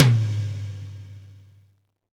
TOM XTOMM0AL.wav